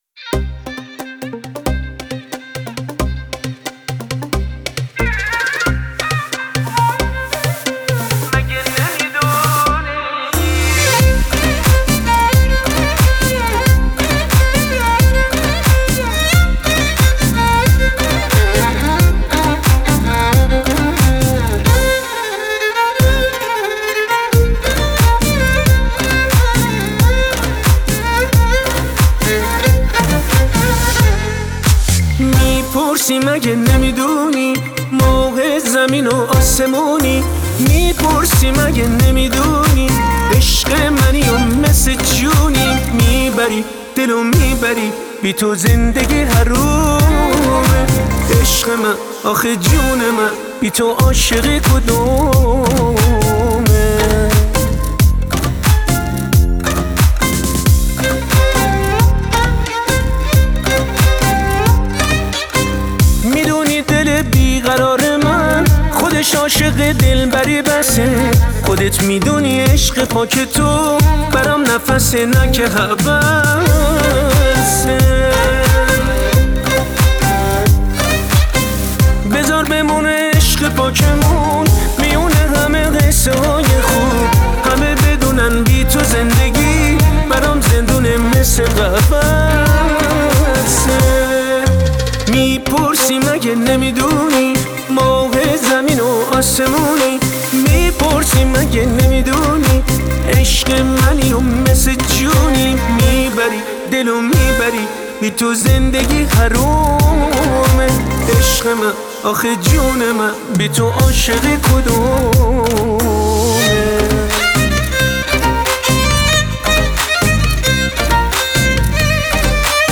پاپ های جدید دانلود آهنگ های جدید